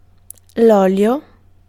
Ääntäminen
Ääntäminen Tuntematon aksentti: IPA: /ˈɔ.ljɔ/ Haettu sana löytyi näillä lähdekielillä: italia Käännös Ääninäyte Substantiivit 1. oil US UK Suku: m .